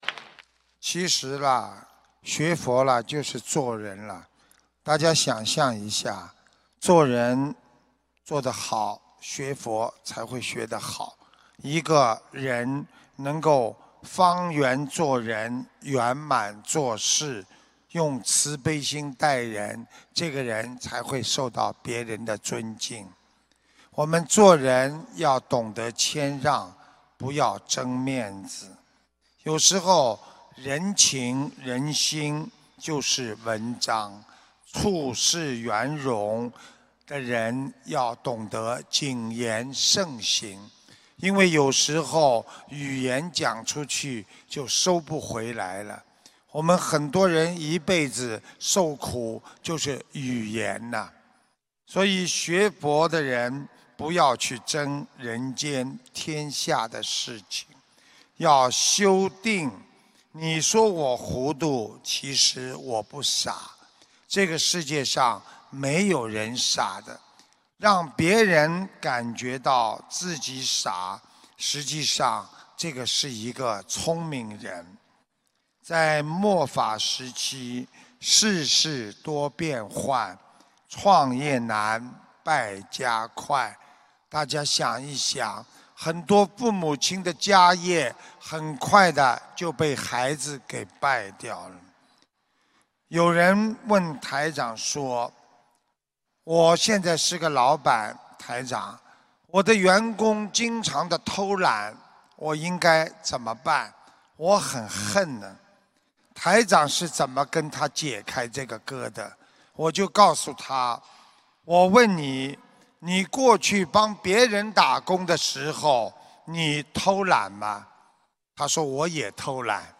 目录：☞ 2016年9月_加拿大_温哥华_开示集锦